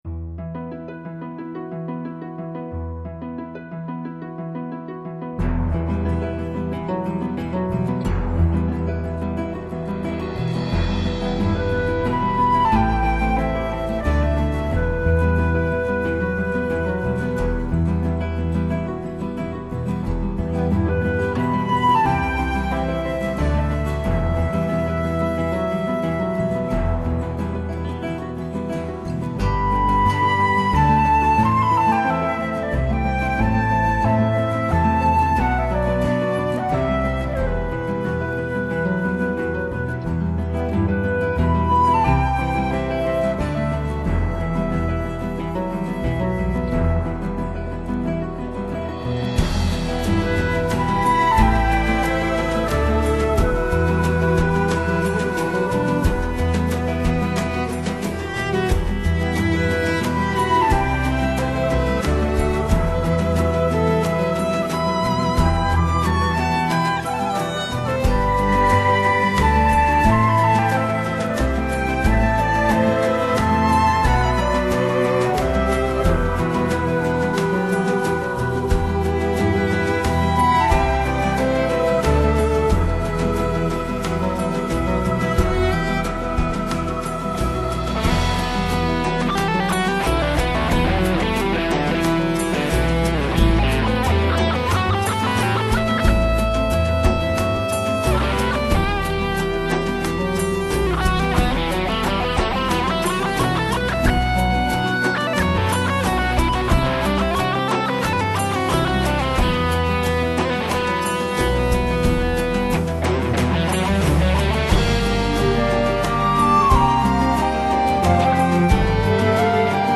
其中既有原创作品，也包括了著名的古典乐曲和一些非电声乐器演奏，其中大多数作品都有优美的MOR摇摆舞旋转动作。